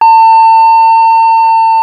ihob/Assets/Extensions/RetroGamesSoundFX/Ringing/Ringing06.wav at master
Ringing06.wav